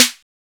Snare SwaggedOut 3.wav